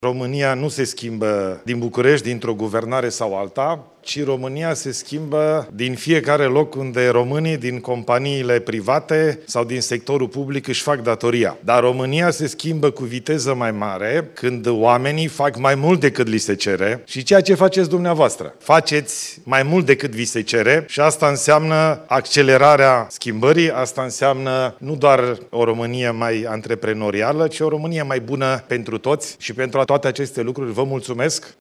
În fața antreprenorilor prezenți la Romanian Business Leaders Summit, Ilie Bolojan a spus miercuri, 8 mai, că îi roagă pe români să meargă la vot, pe 18 mai și să se gândească la statutul României în lume.